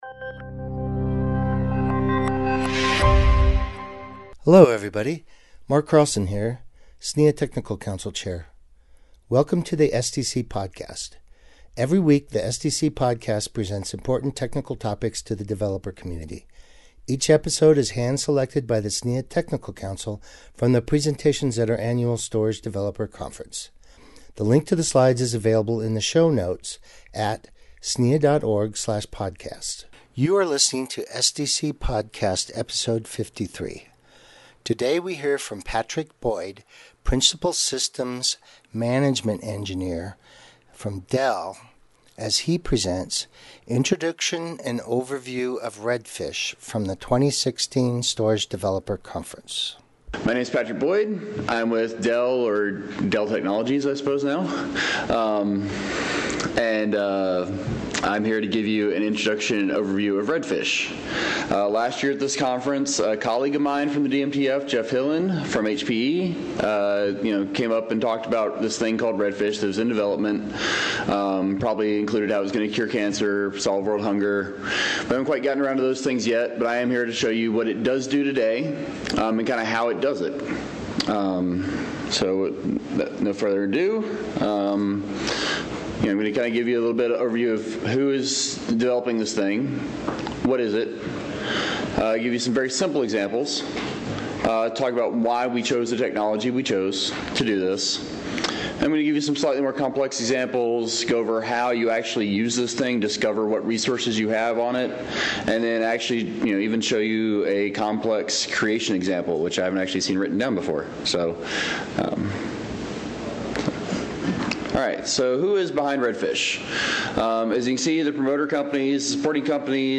Podcast Presentation